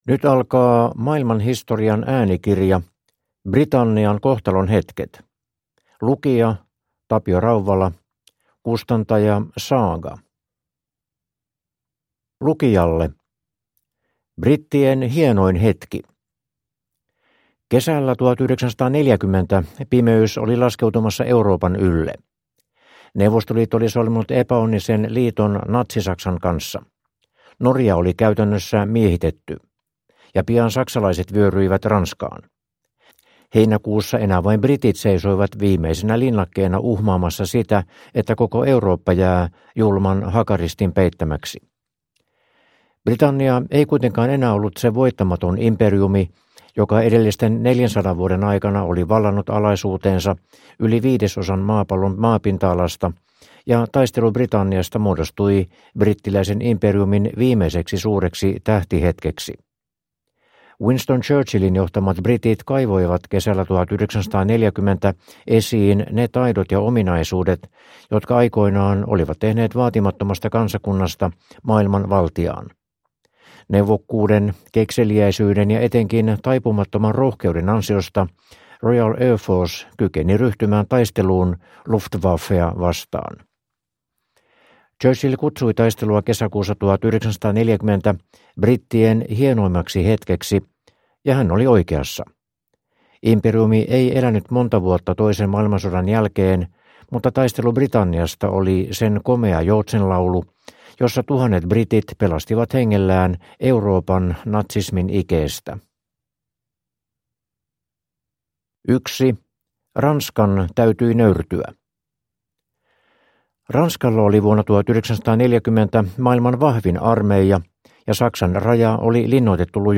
Britannian kohtalonhetket (ljudbok) av Maailman Historia